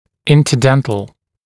[ˌɪntə’dentl][ˌинтэ’дэнтл]межзубный